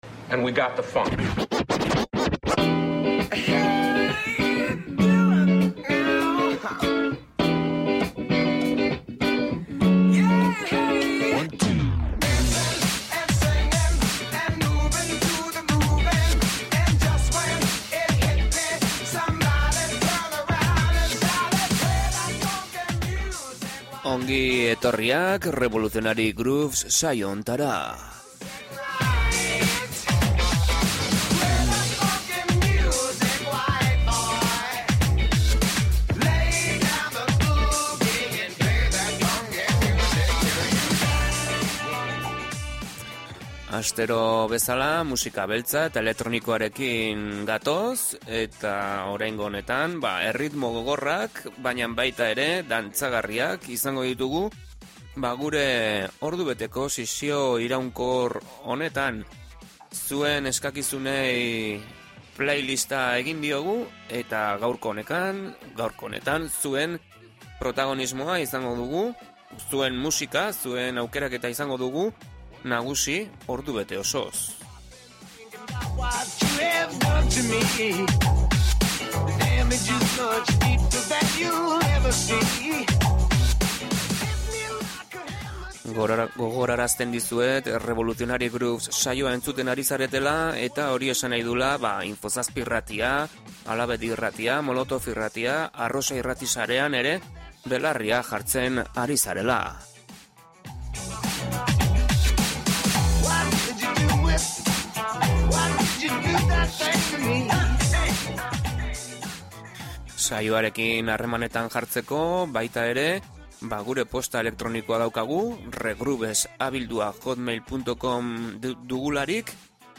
REVOLUTIONARY GROOVES: 21.mende hasieran entzuten zen r&b edo soul berria eta hiphop abestiak entzungai